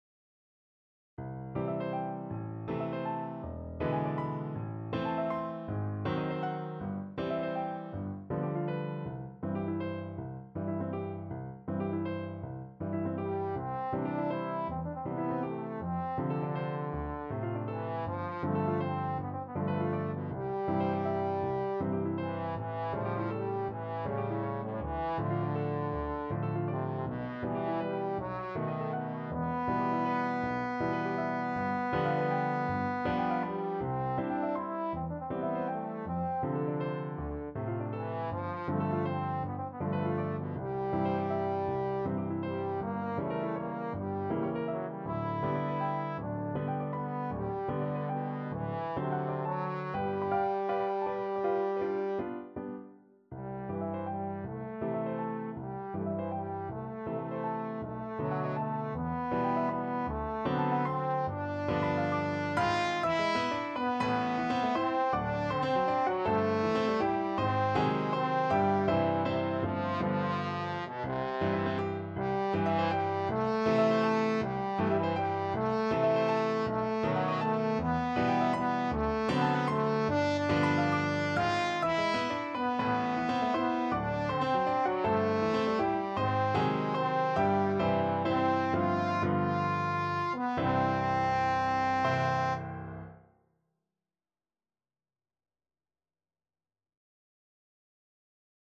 Classical Leoncavallo, Ruggero Mattinata Trombone version
6/8 (View more 6/8 Music)
C major (Sounding Pitch) (View more C major Music for Trombone )
A3-F5
Trombone  (View more Intermediate Trombone Music)